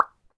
Add "pop" sound when a crop is ready
pop.wav